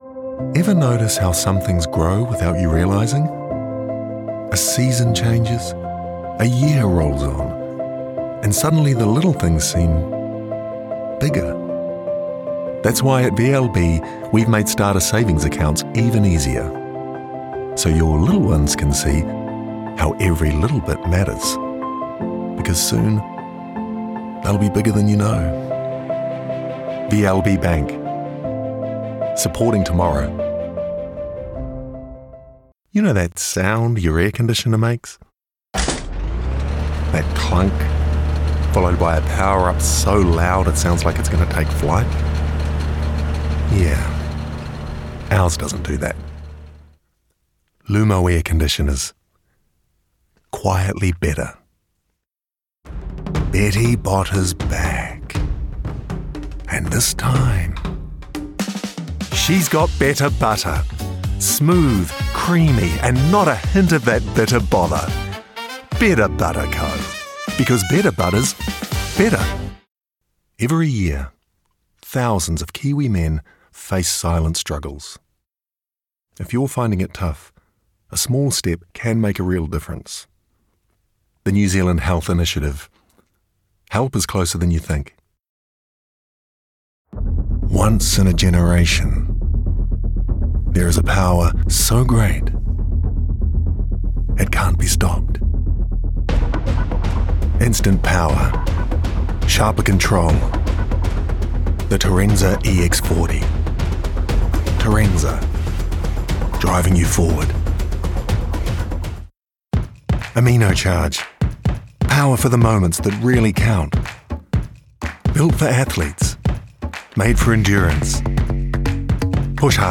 Adult, Mature Adult
Accents: new zealand | natural
COMMERCIAL 💸 NARRATION
conversational sincere trustworthy